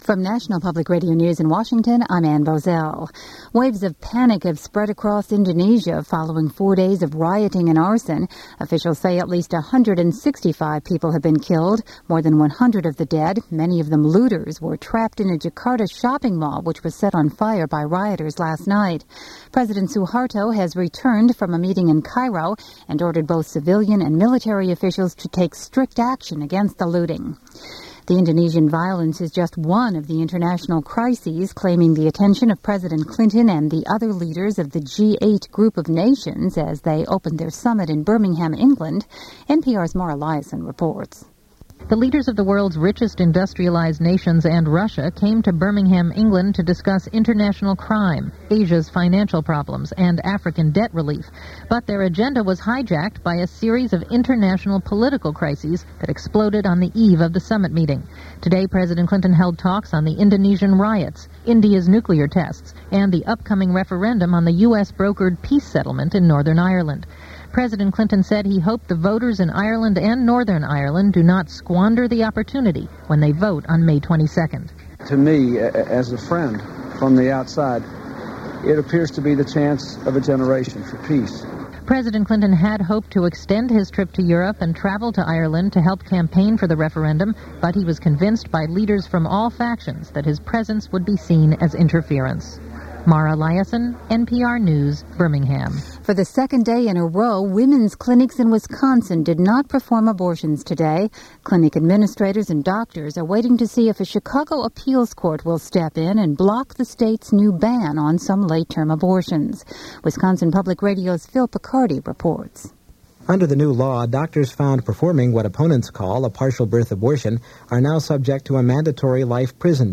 And that’s just a small slice of the news that happened, this day 20 years ago, as reported by NPR.